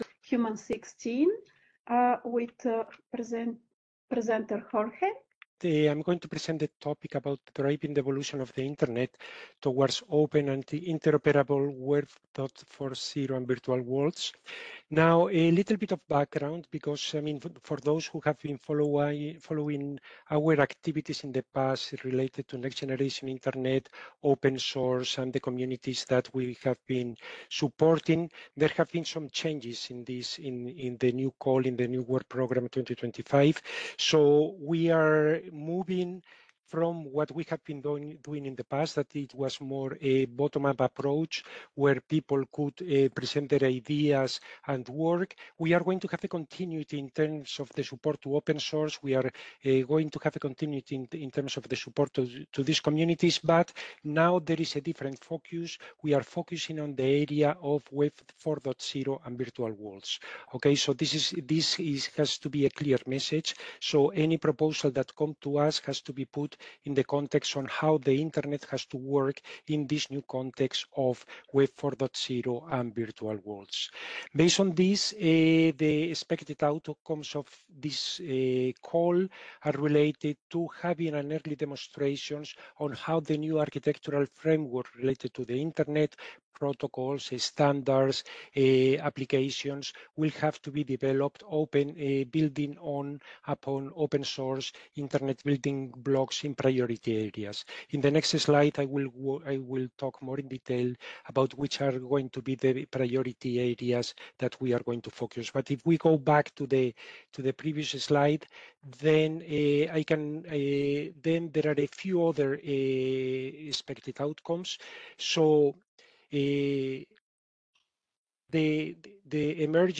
View the presentation from the EC during the ICT infoday, read the transcript or listen to the recording (local mirror, original on YouTube).